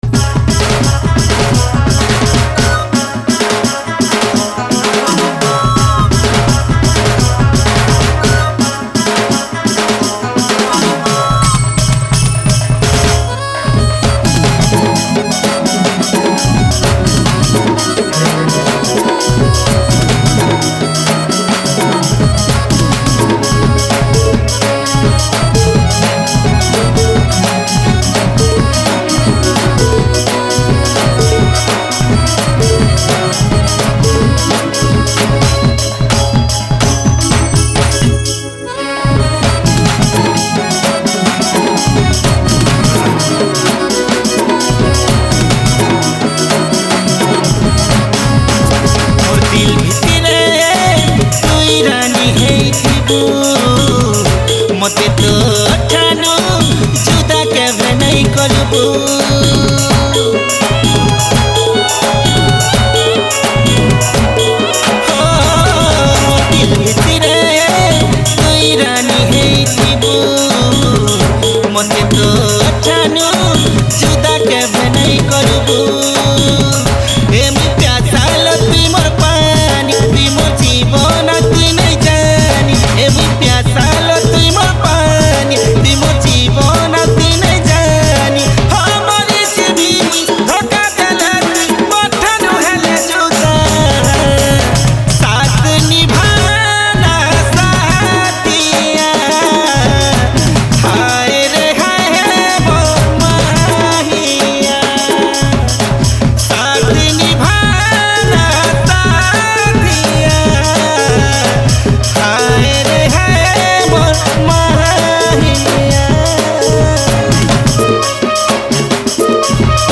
Sambalpuri Songs